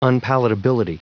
Prononciation du mot unpalatability en anglais (fichier audio)
unpalatability.wav